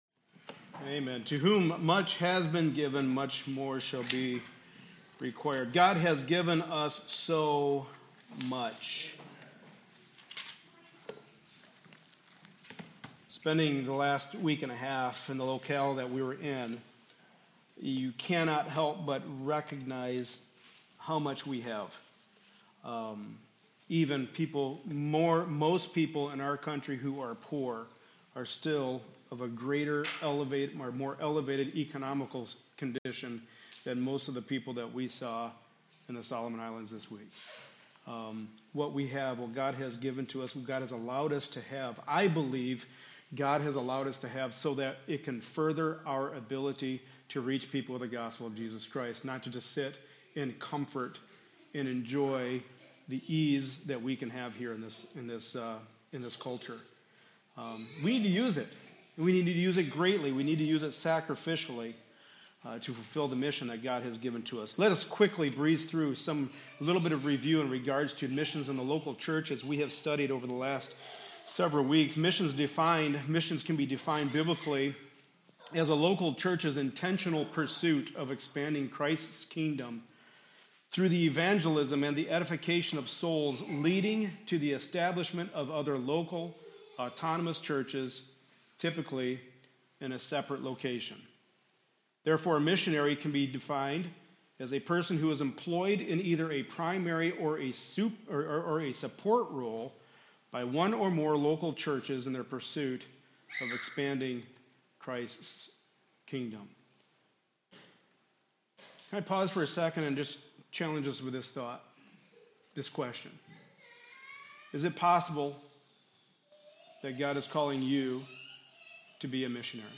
Passage: 2 Corinthians 11-12 Service Type: Sunday Morning Service